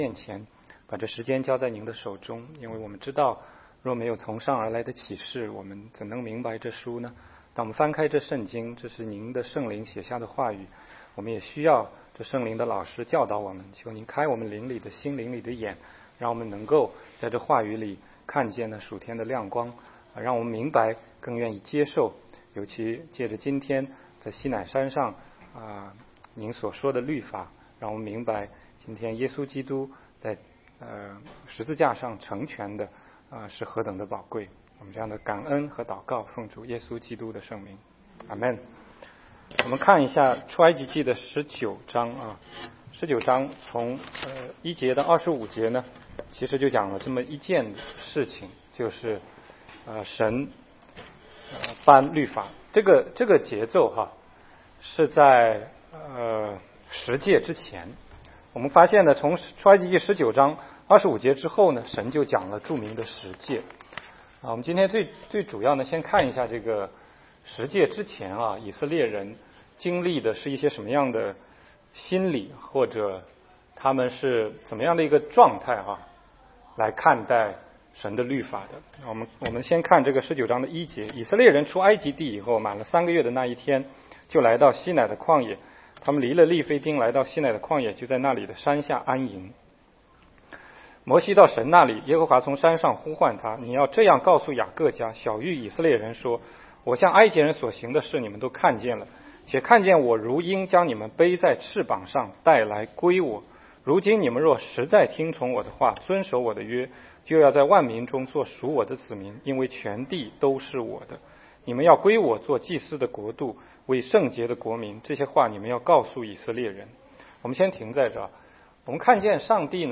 16街讲道录音 - 西奈山和锡安山